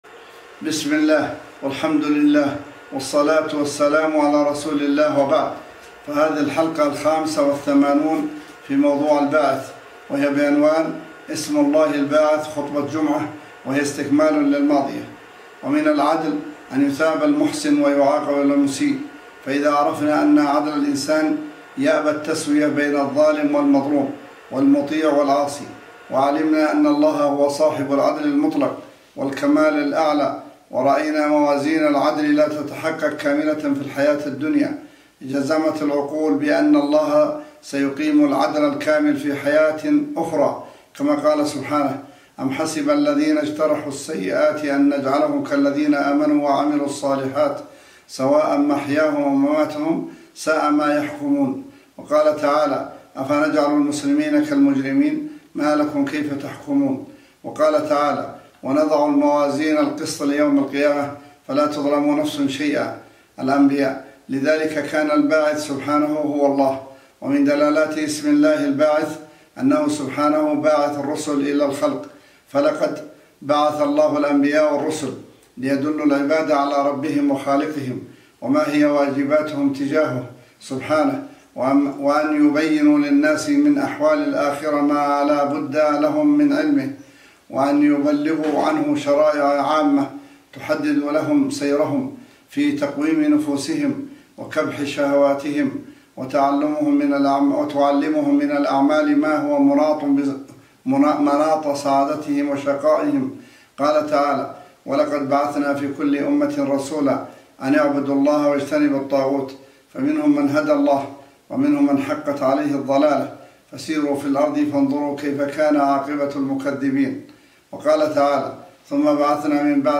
الحلقة الخامسة والثمانون في موضوع (الباعث) وهي بعنوان :           * اسم الله الباعث – خطبة جمعة :